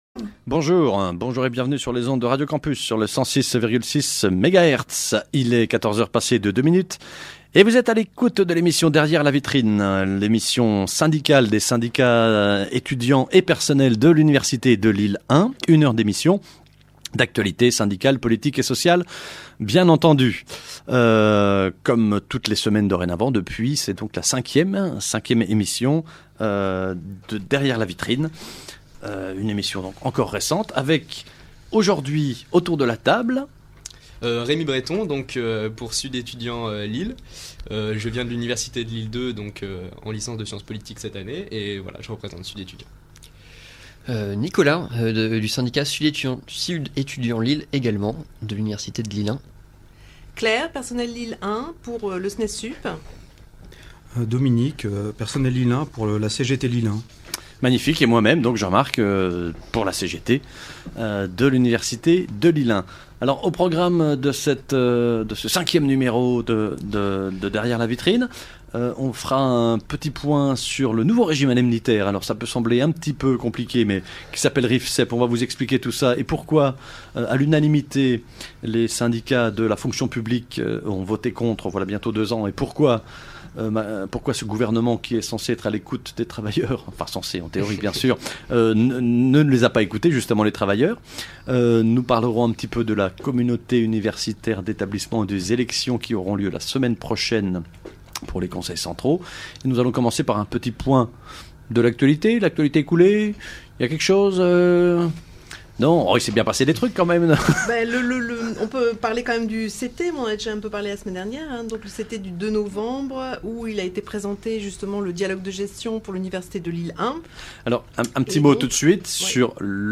« Derrière la Vitrine », c’est l’émission des syndicats (étudiants et personnels) de l’université Lille1, sur Radio Campus Lille (106,6 FM), tous les jeudis, de 14h à 15h. On y parle de l’actualité universitaire et des luttes sociales.